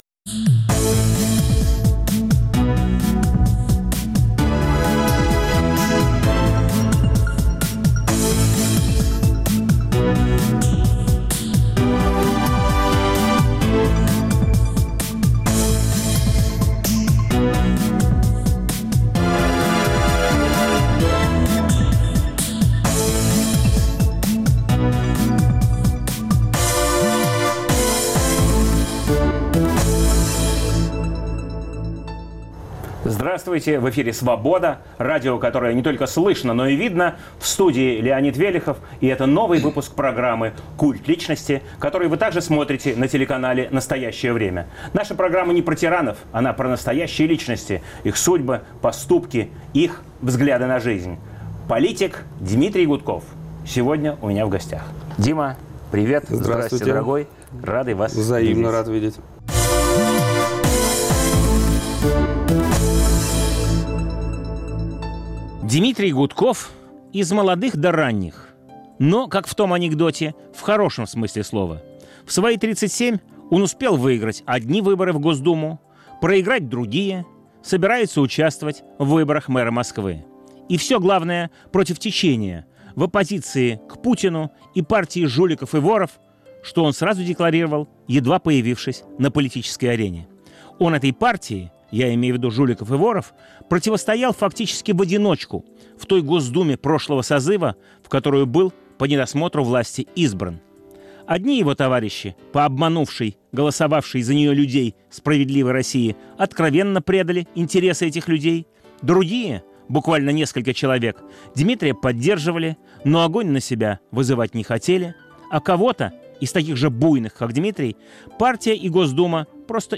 Новый выпуск программы о настоящих личностях, их судьбах, поступках и взглядах на жизнь. В студии политик-оппозиционер Дмитрий Гудков.